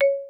PaddleHit.wav